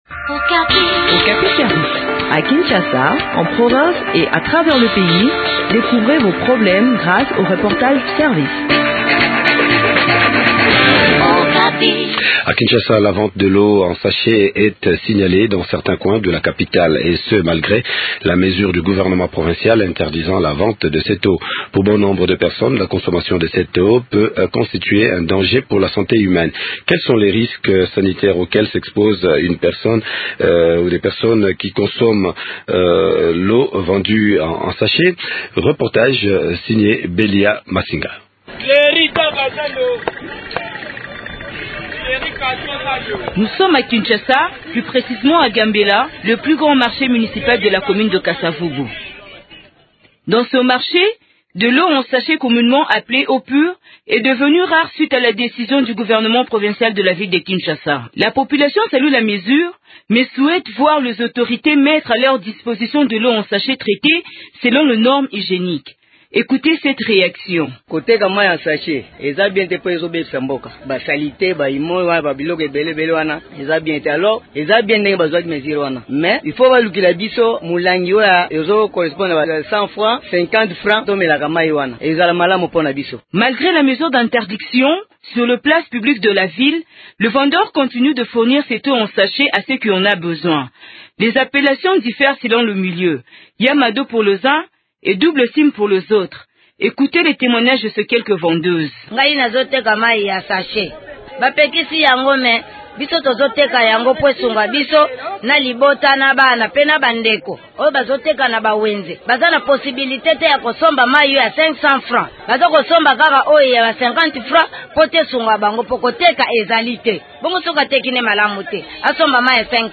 entretient